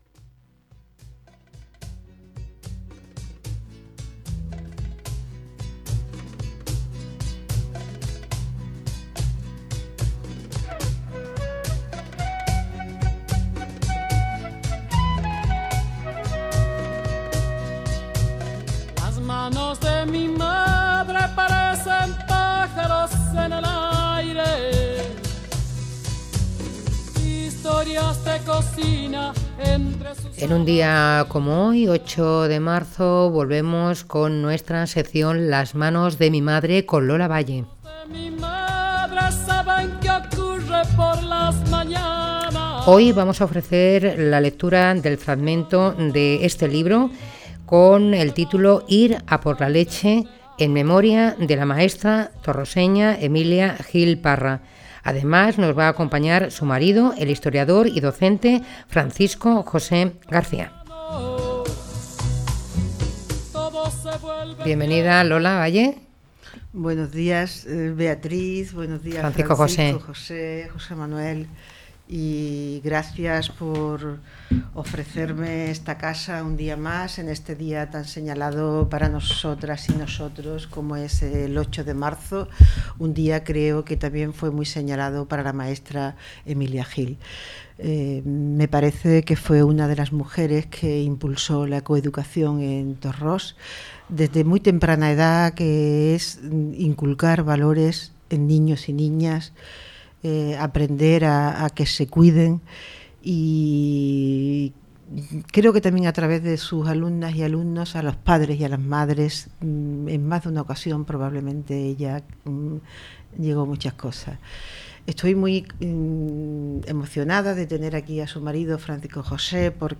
Para ella leímos el fragmento, "Ir a por la leche".